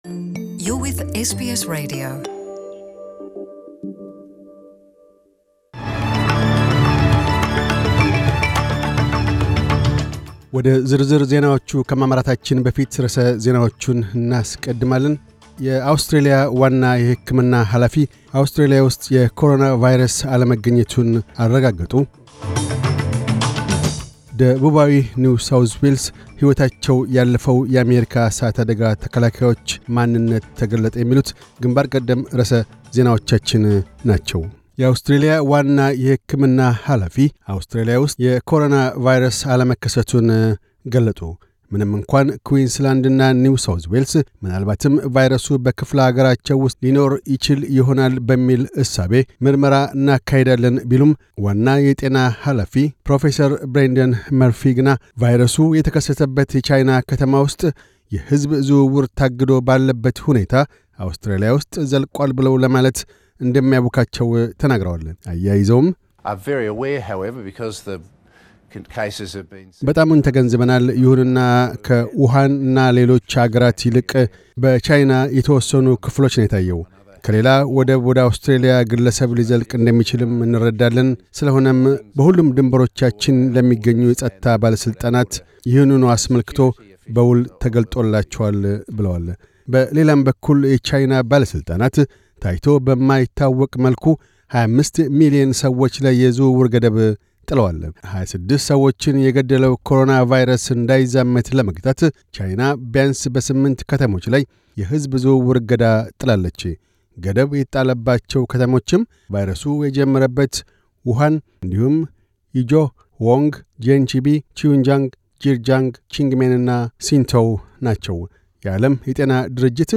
News Bulletin 2401